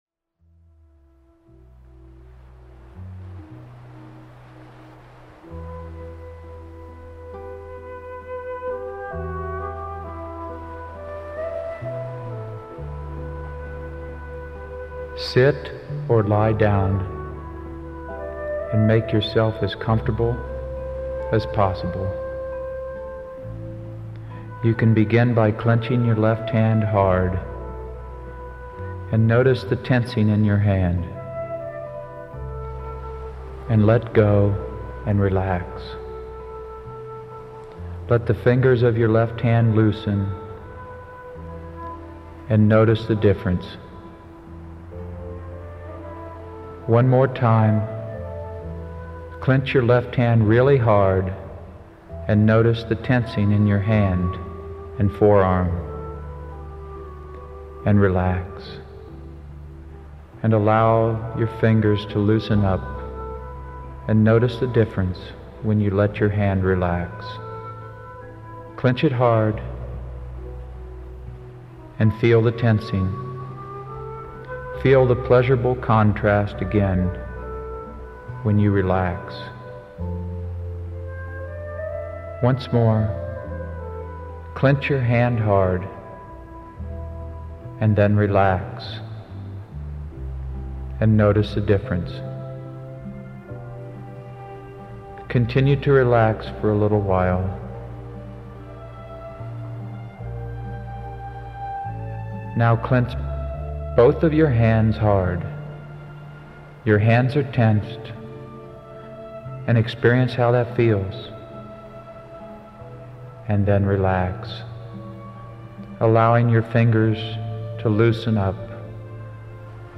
1-muscular-relaxation1-short.mp3